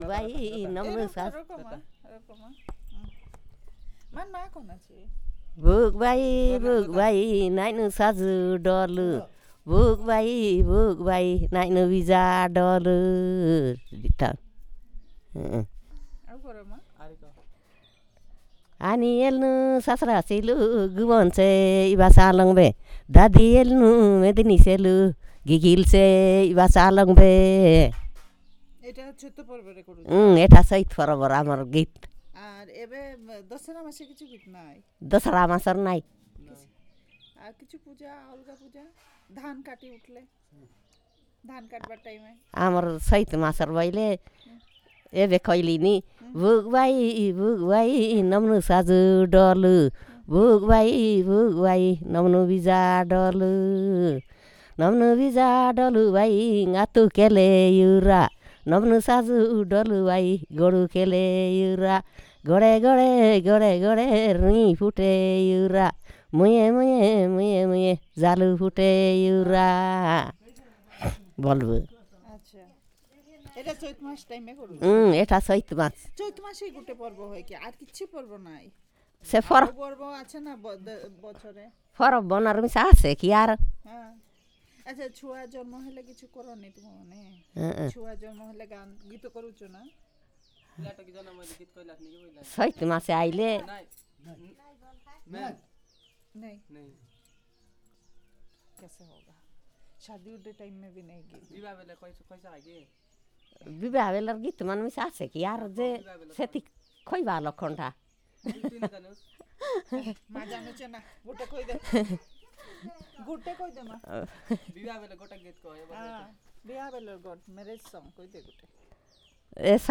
Perfomance of folk song